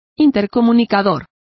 Complete with pronunciation of the translation of intercom.